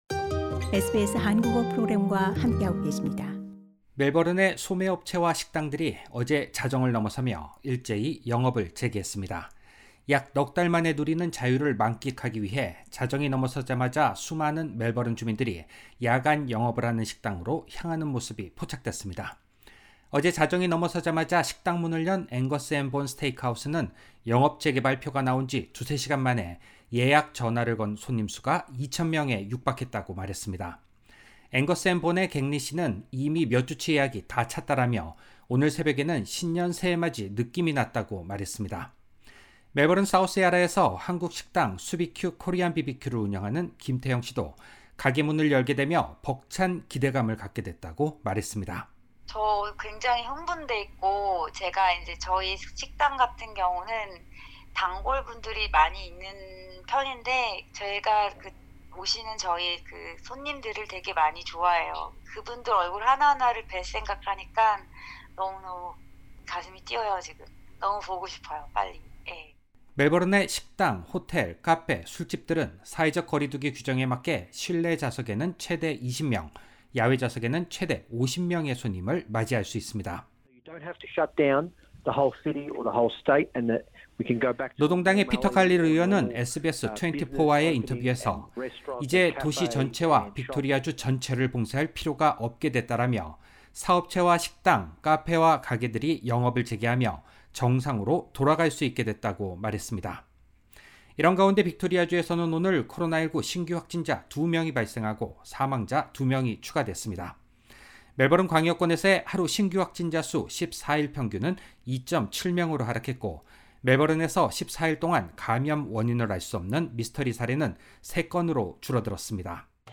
28_10_audio_news_melbourne_popdcast.mp3